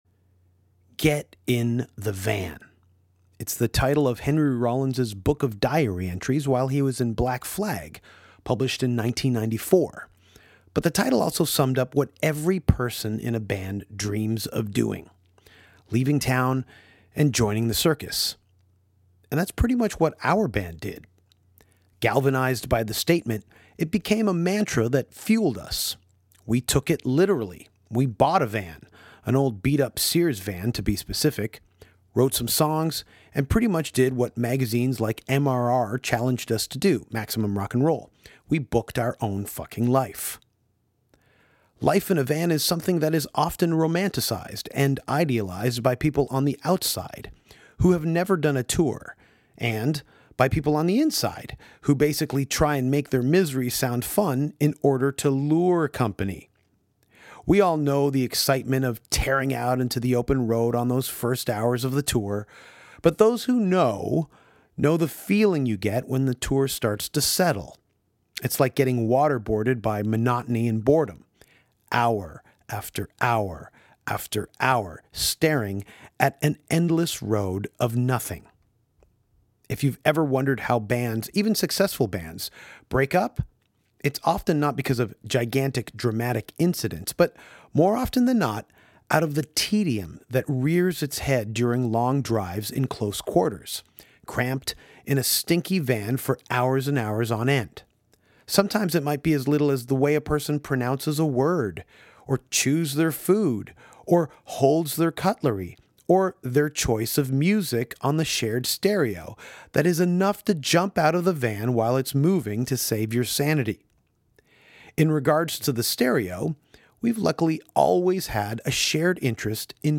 Comedian Jim Florentine is finally on the podcast and talks to Danko about his comedy albums, Awful Jokes From My First Comedy Notebook, I’m Your Saviour and I Got The House, opening for Slayer, Californication, VH1’s That Metal Show and … Continued...